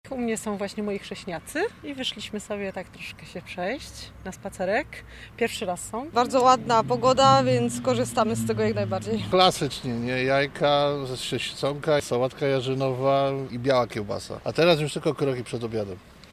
"Od rana głównie się jadło, teraz trzeba się rozruszać" - mówili mieszkańcy w rozmowie z naszym reporterem.